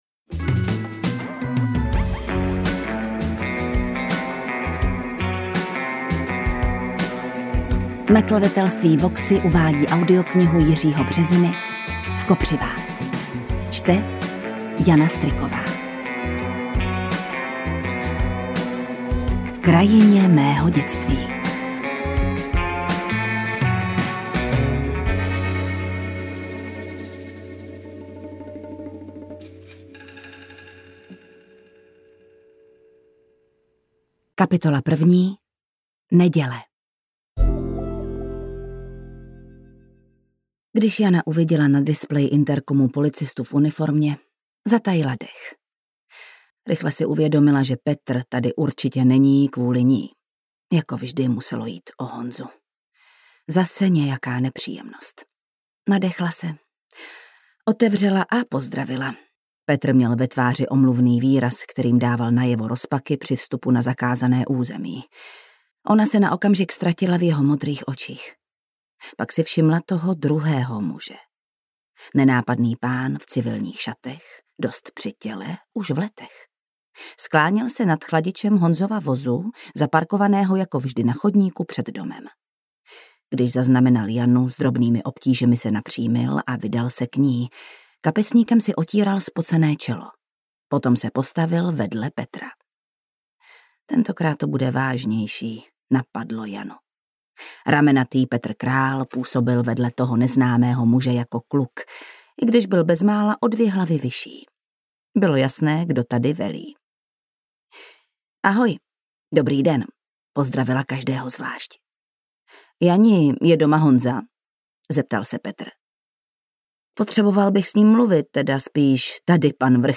Interpret:  Jana Stryková
AudioKniha ke stažení, 64 x mp3, délka 8 hod. 39 min., velikost 471,5 MB, česky